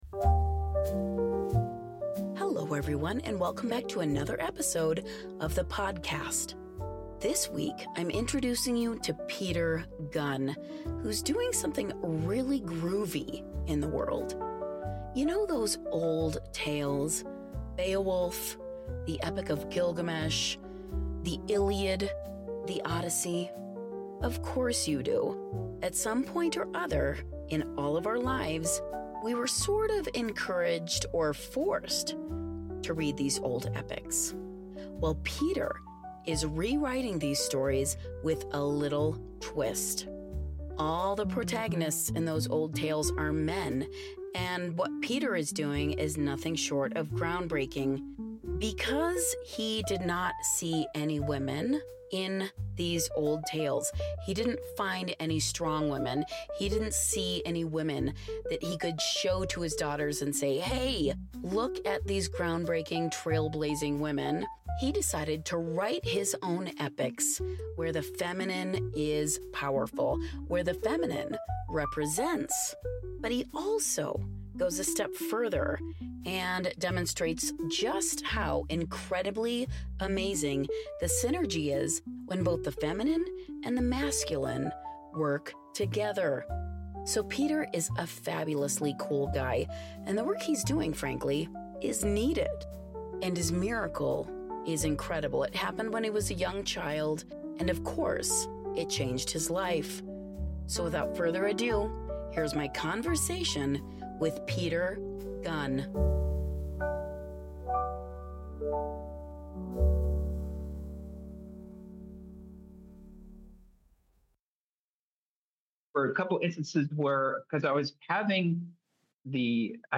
We had a great time recording the interview!